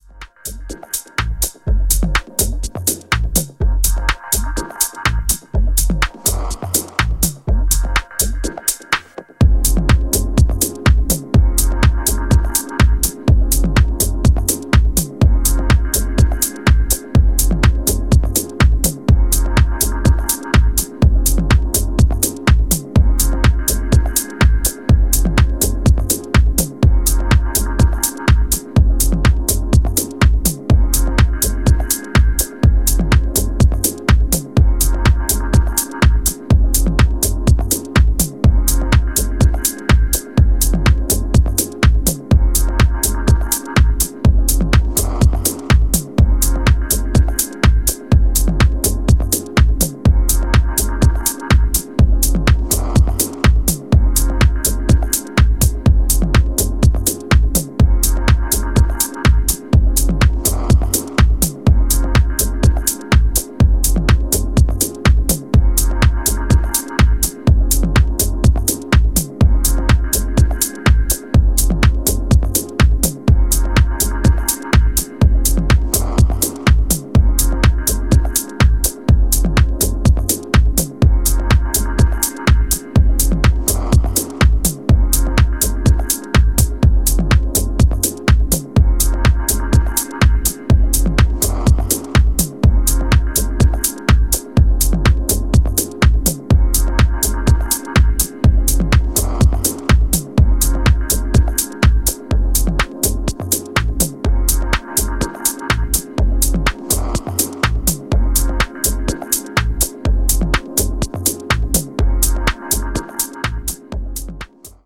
some playful hi hats and other wordly sounds